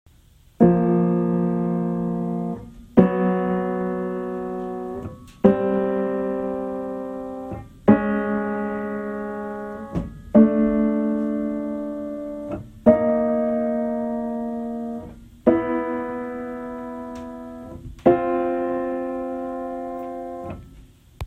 This is a free analysis generated by a recording of M3’s and P4’s submitted to me by one of my subscribers.
P4, unfiltered
3. Listen to the unfiltered recordings and try to hear the specific beating partial within the unfiltered recording.